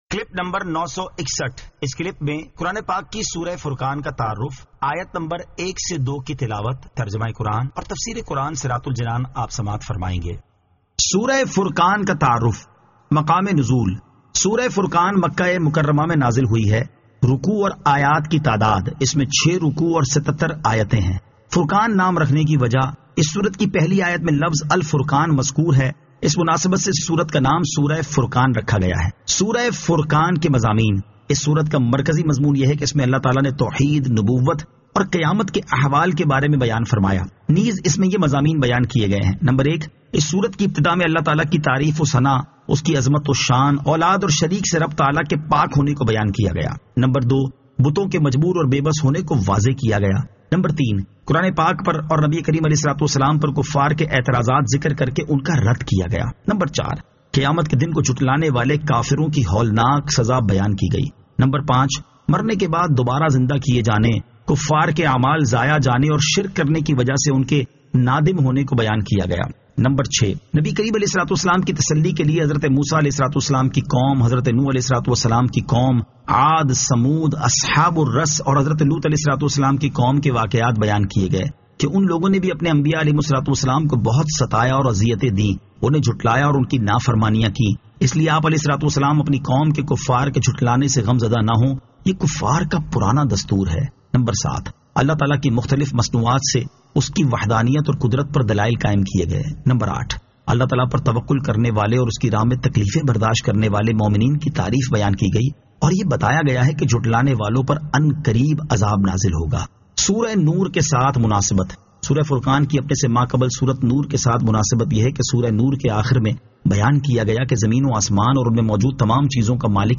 Surah Al-Furqan 01 To 02 Tilawat , Tarjama , Tafseer